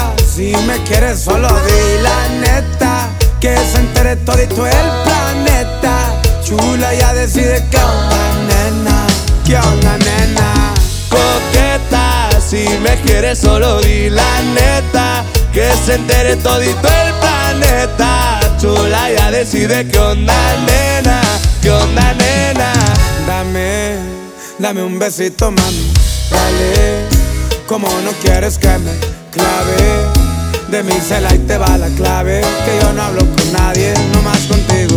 Скачать припев
Música Mexicana Latin